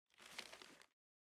打开纸张.ogg